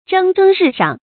注音：ㄓㄥ ㄓㄥ ㄖㄧˋ ㄕㄤˋ
蒸蒸日上的讀法